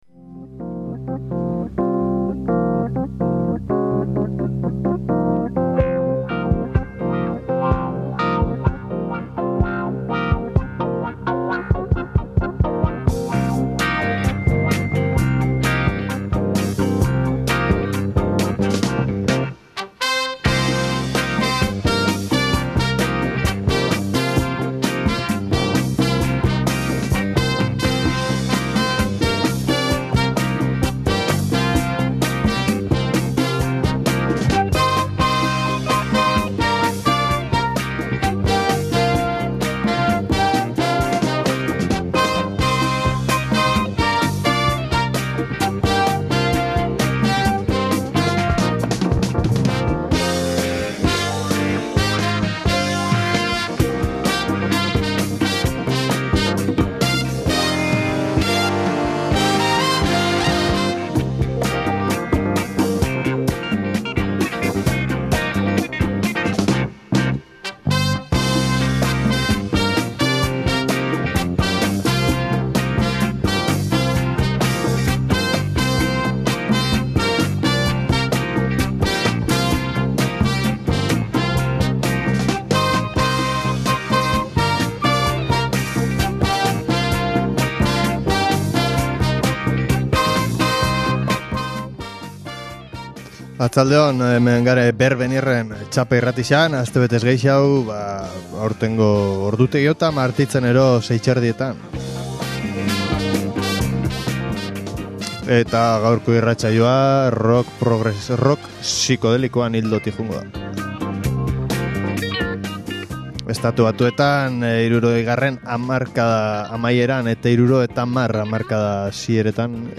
Heavy rock sikodelikoa, garagea eta sikodelia, folk sikodelikoa…. 60 hamarkadaren amaiera eta 70 hamarkadaren hasieran, AEBn egiten zen Sikodeliaren barruan entzun ahal ziren doinu ezberdin ugari gaurko BERBENIR-en